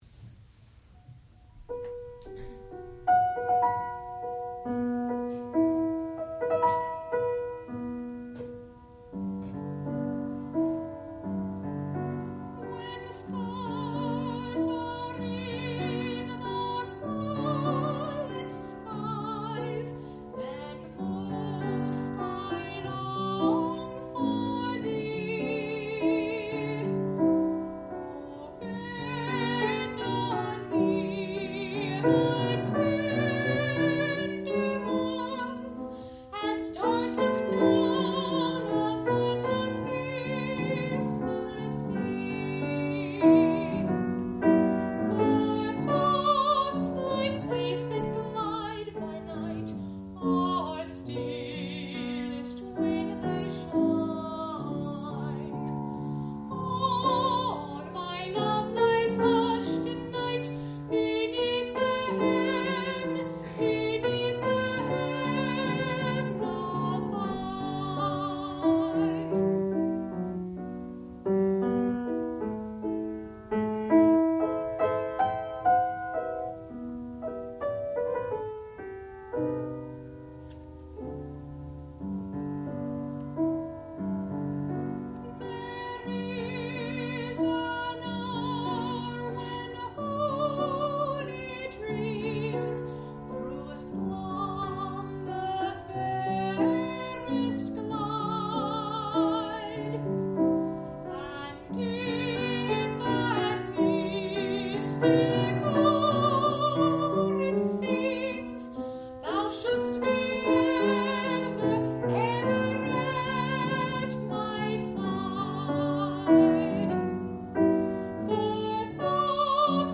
sound recording-musical
classical music
Qualifying Recital
mezzo-soprano